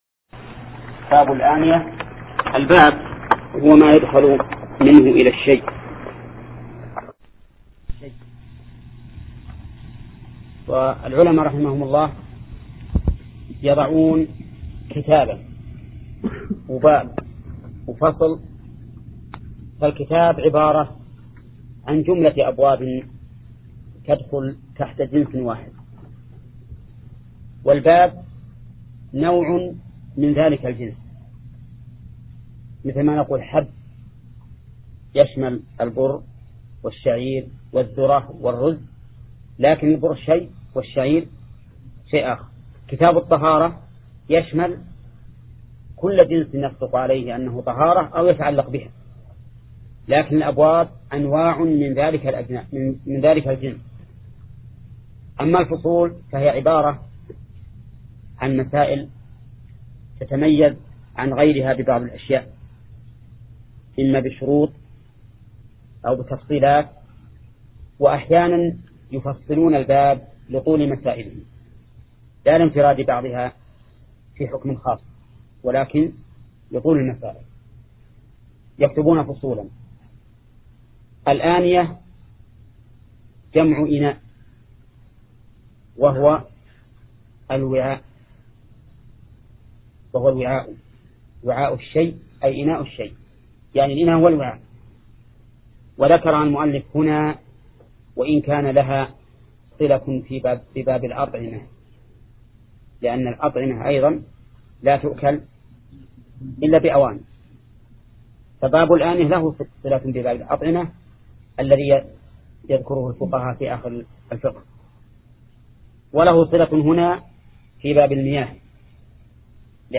درس (4) : باب الآنية